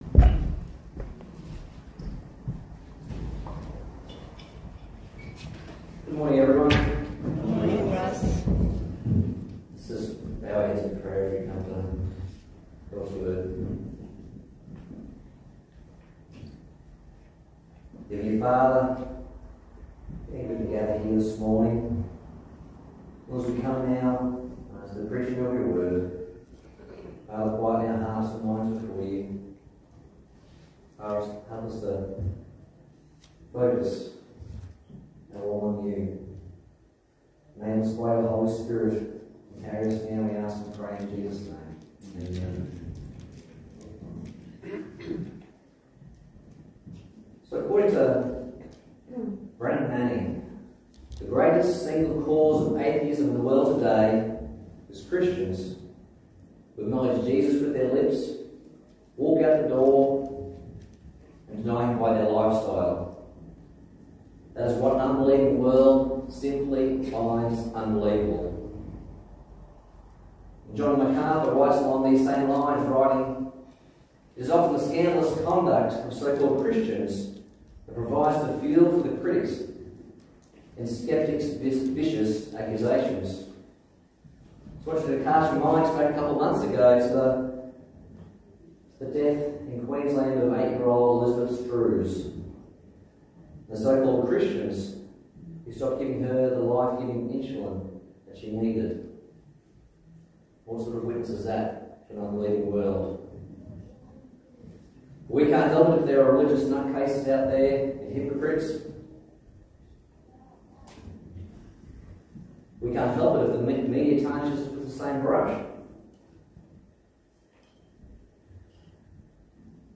Passage: 1 Peter 2:11-12 Service Type: Sunday Morning